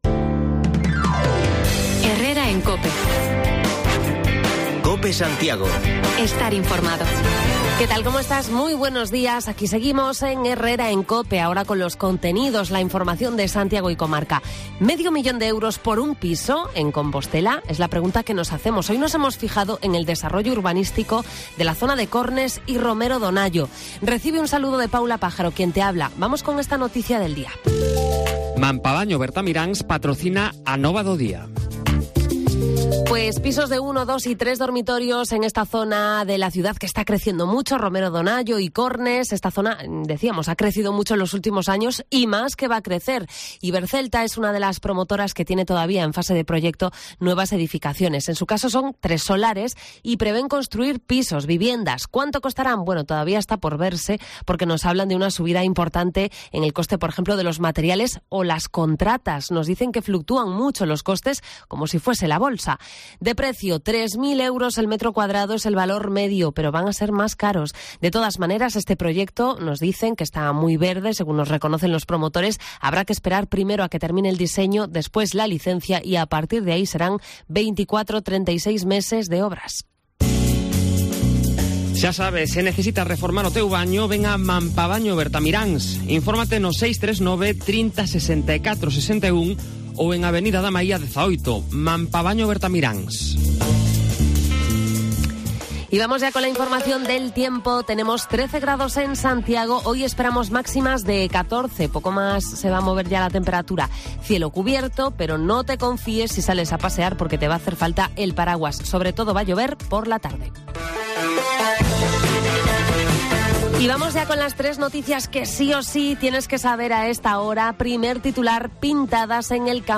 Nos acercamos hasta el Campus Sur, donde vuelven a proliferar pintadas en edificios educativos y mobiliario urbano: recogemos opiniones en la zona.